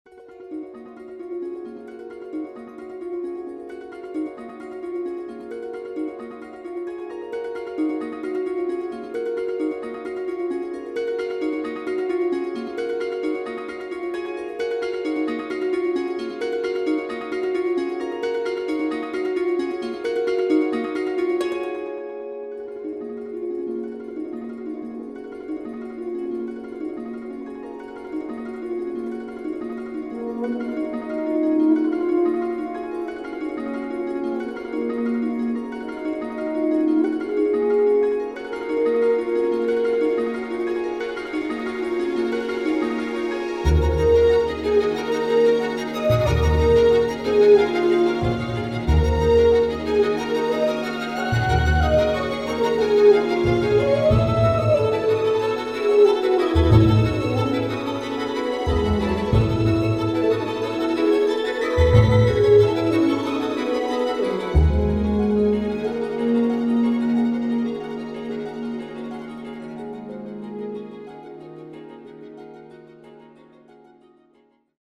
delightfully wicked score